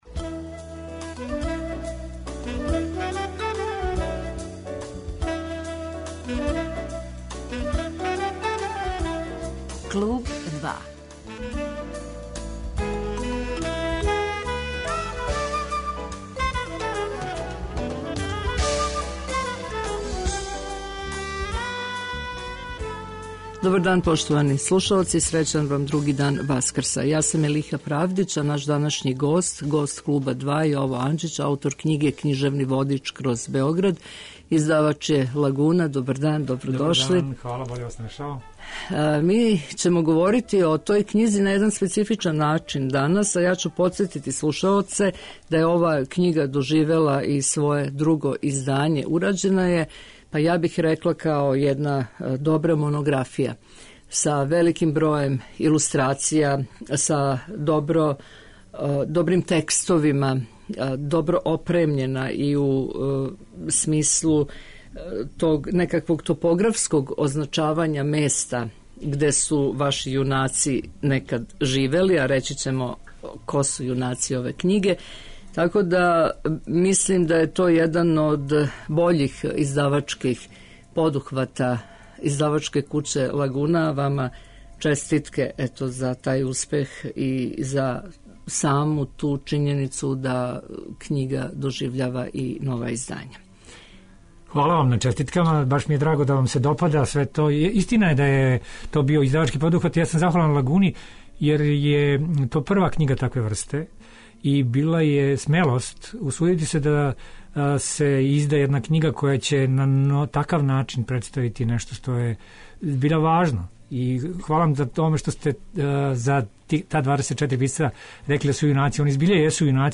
Где су њихова кључна места сусрета, како су се укрштавале њихове судбине, која су то места која данас врло прецизно, топографски можемо одредити као њихова састајалишта, окосница је за данашњи разговор.